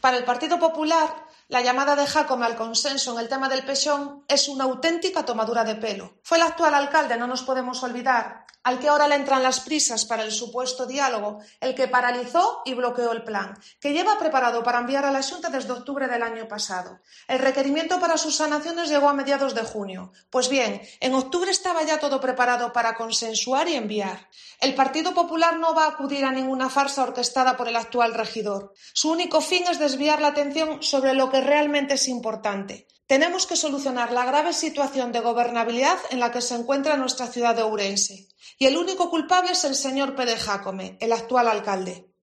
Declaraciones de la concejala del PP, Sonia Ogando, sobre la convocatoria de Jácome